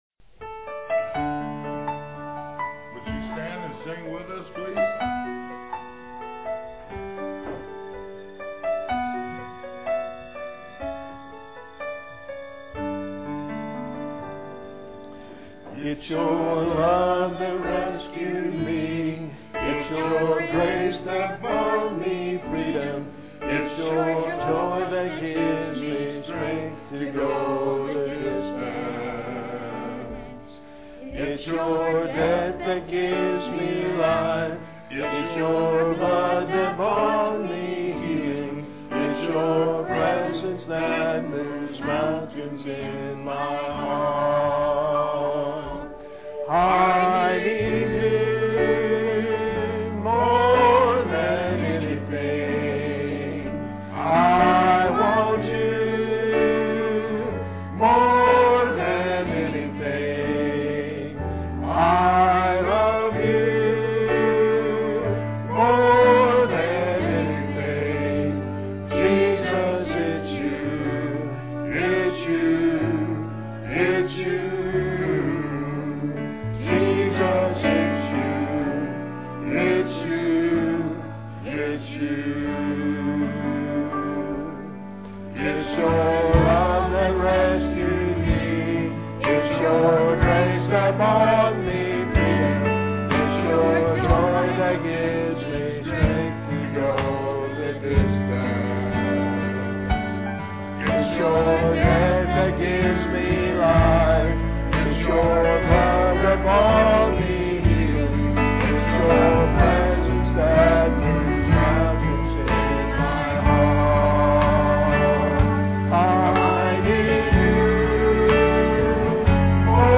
PLAY Prophecy Series, Part 2, Sep 17, 2006 S cripture: Matthew 24:1-14. Scripture Reading